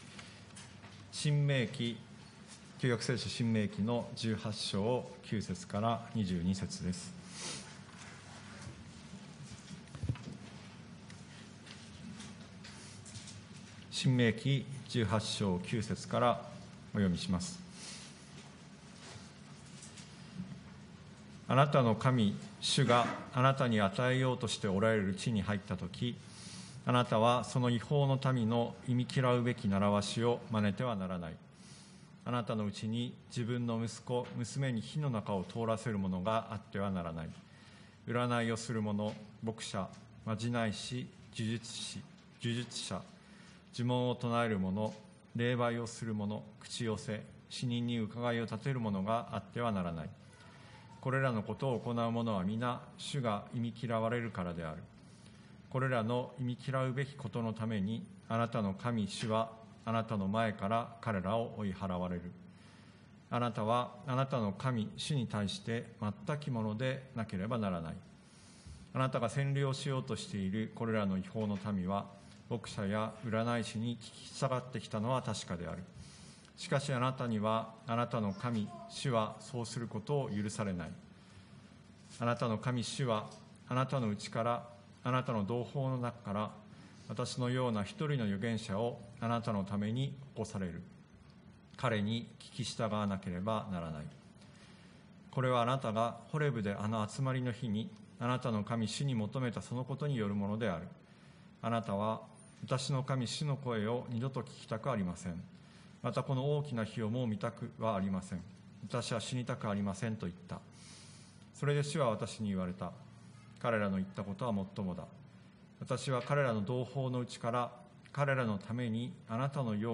TOP > 礼拝メッセージ(説教) > イエスと占い師の違い イエスと占い師の違い 2021 年 12 月 5 日 礼拝メッセージ(説教